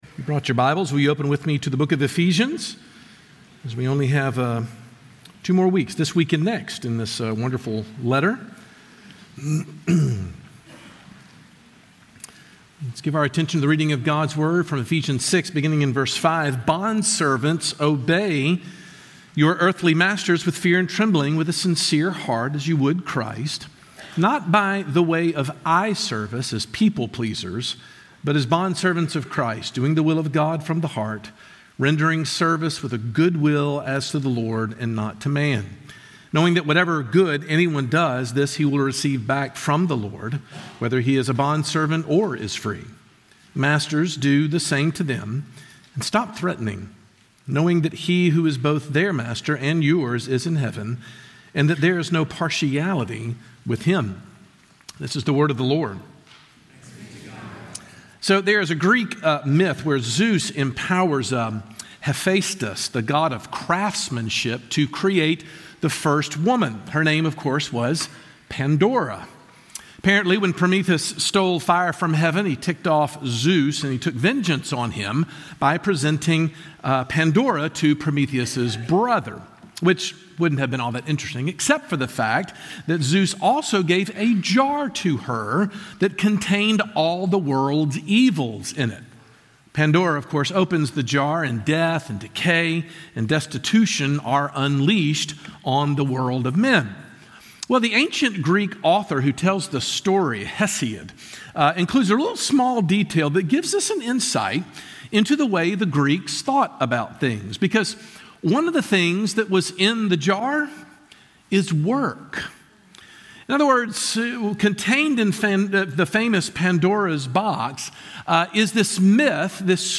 No surprise that Paul takes up this topic in Ephesians 5 and gives some of life changing insight into how Christians are to view their jobs. Sermon Points: